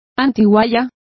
Complete with pronunciation of the translation of antiques.